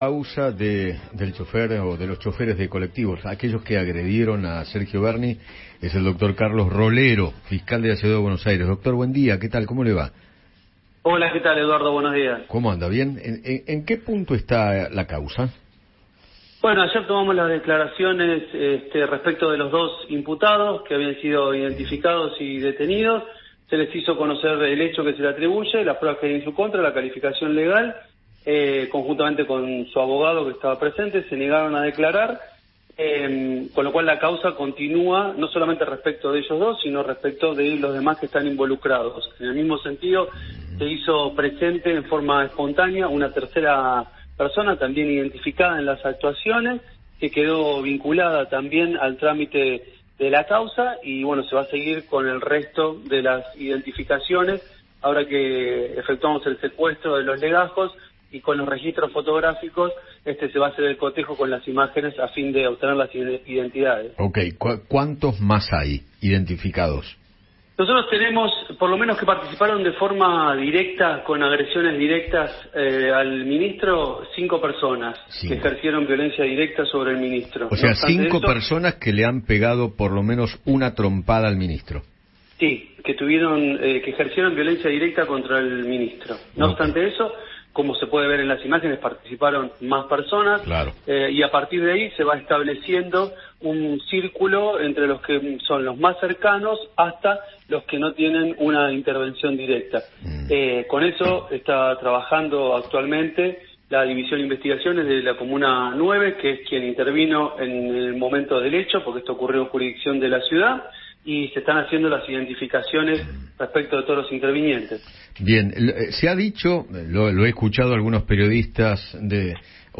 El fiscal Carlos Rolero conversó con Eduardo Feinmann acerca de cómo sigue la causa por la agresión a Sergio Berni.